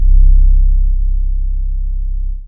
YM Sub 7.wav